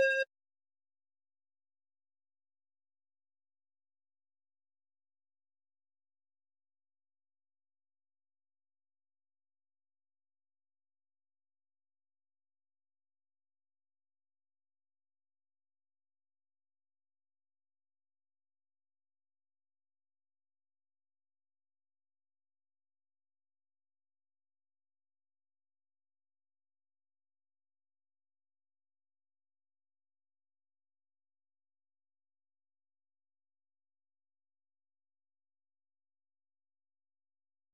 FX / 911 Beep